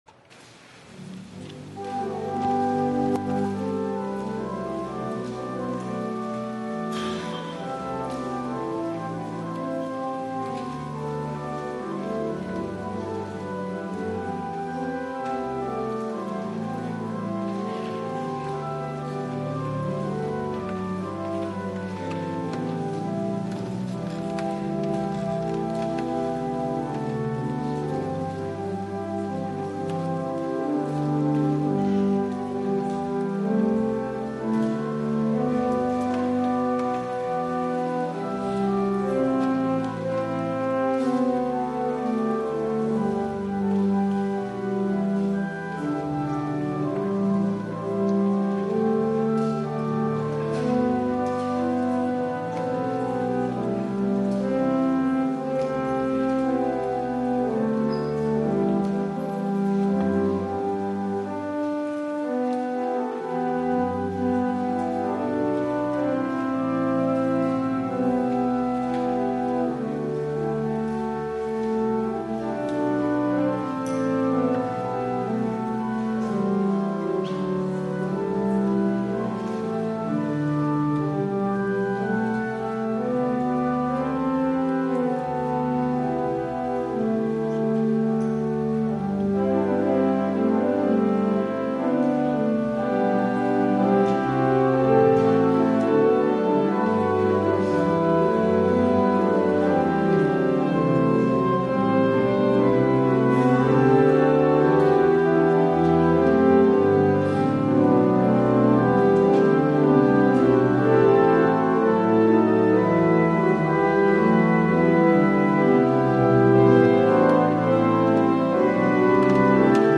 Morningside Presbyterian Church, Atlanta
THE OFFERTORY